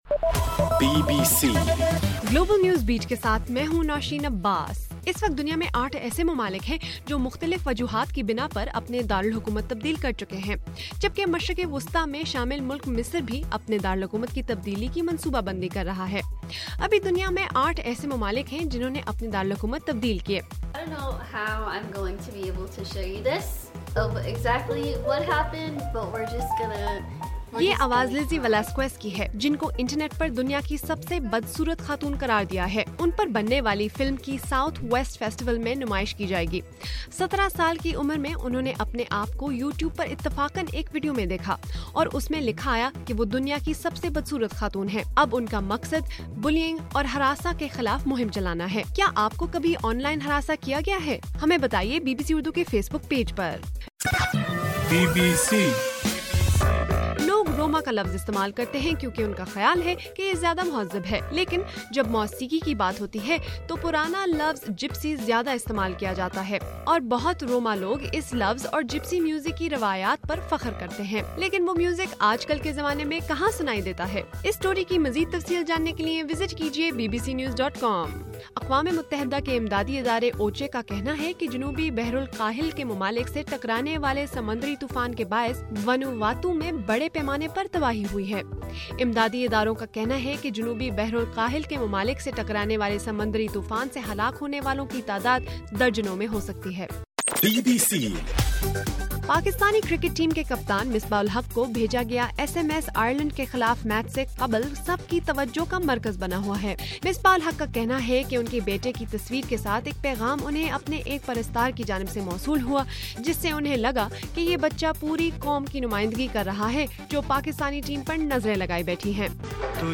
مارچ 14: رات 8 بجے کا گلوبل نیوز بیٹ بُلیٹن